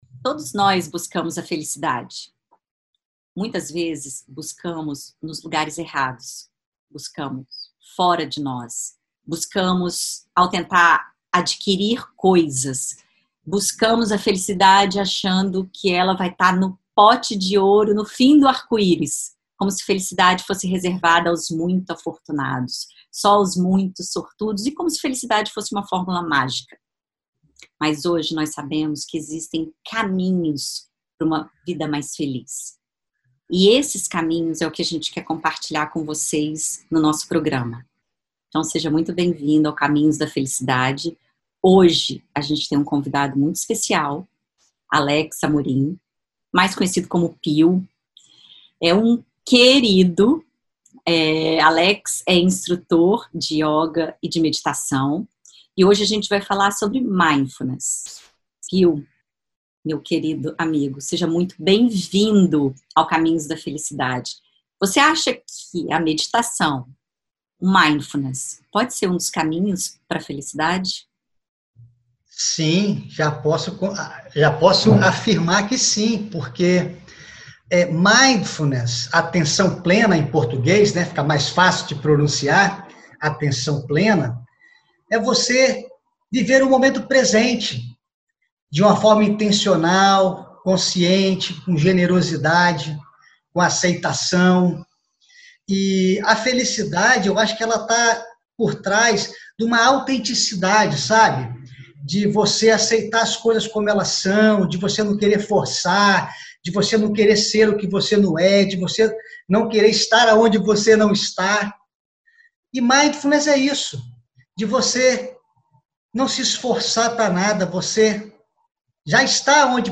Ele fala sobre o conceito de “mindifulness”.